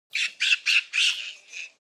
Little Monkey Screech Bouton sonore